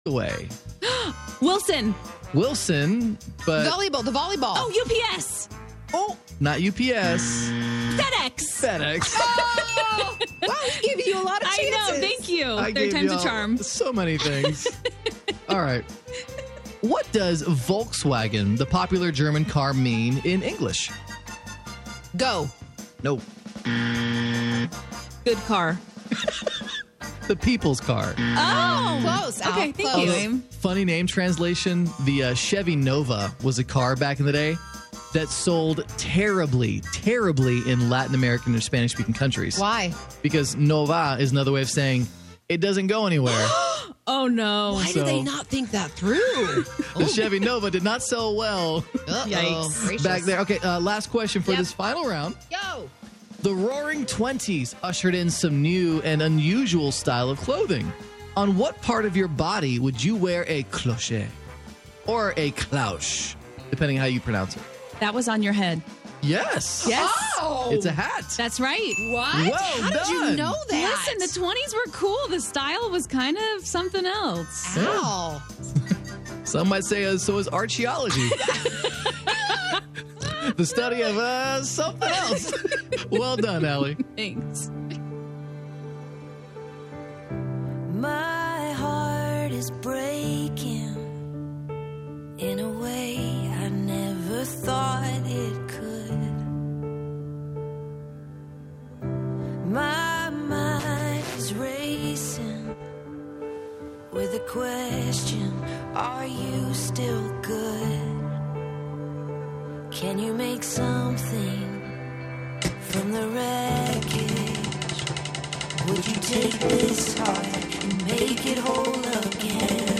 Live from Brooklyn, New York,